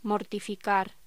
Locución: Mortificar